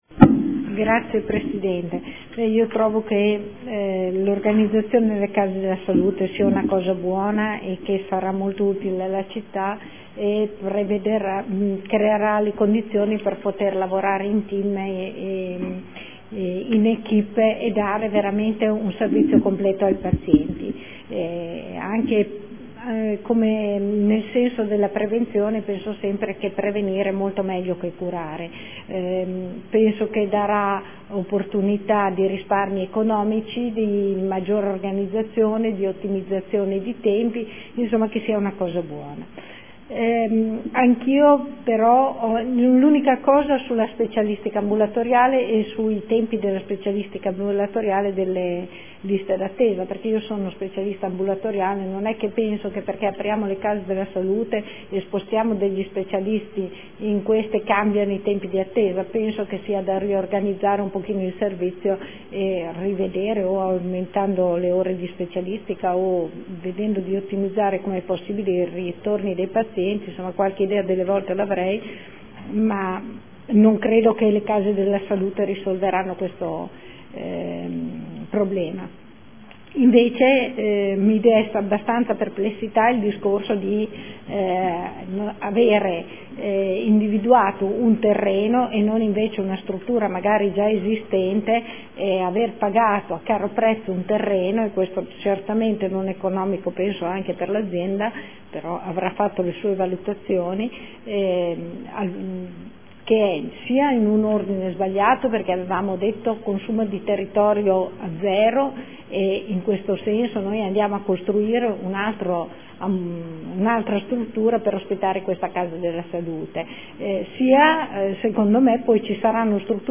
Luigia Santoro — Sito Audio Consiglio Comunale
Seduta del 3/11/2014. Dibattito su ordini del giorno